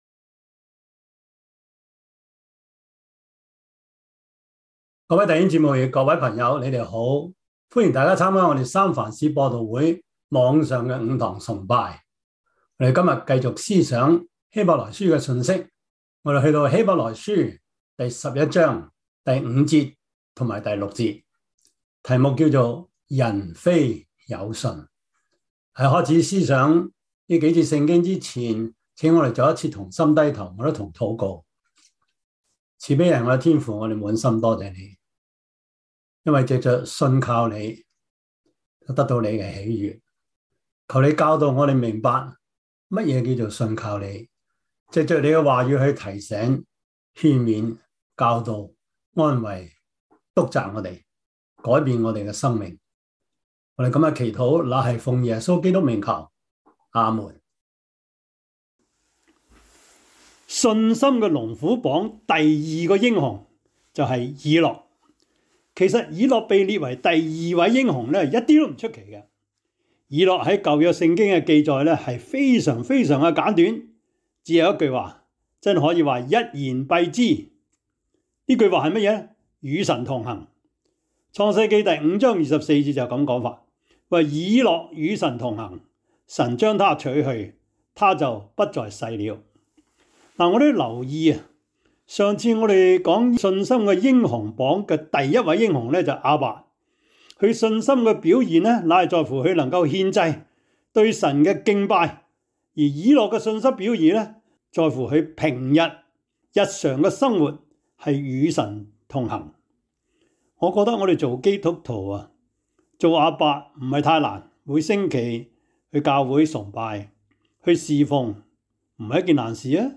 Service Type: 主日崇拜
Topics: 主日證道 « 葡萄園的故事 摩西五經 – 第五課 »